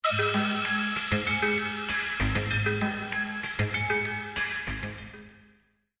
ROLAND D-50
Il offre ici des sonorités nouvelles basées sur une synthèse soustractive numérique et des attaques à base d'échantillions PCM.
Très puissant, très rock, le D-50 sera mis à grosse contribution dans l'album Révolutions comme l'a fait le Synthex dans Rendez-Vous.
Gurgel Strings